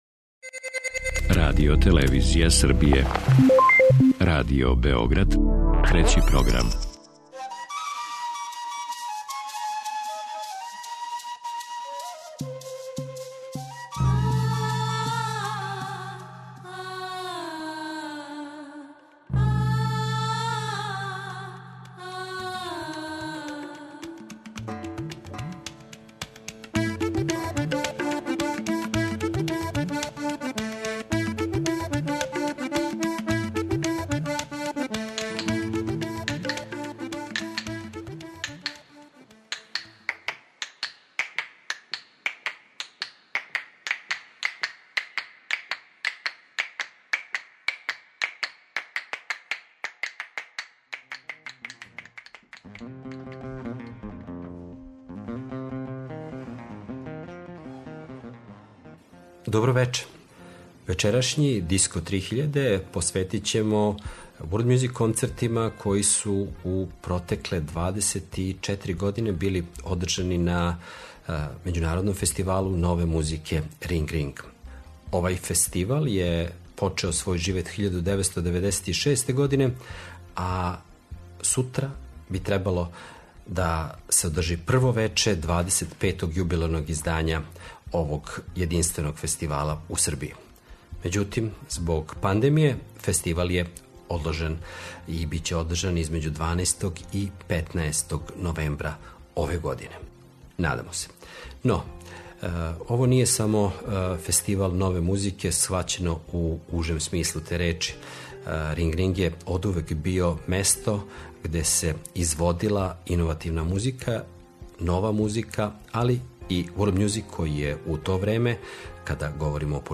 Европски world music
Вечерас се подсећамо неких веома запажених концерата.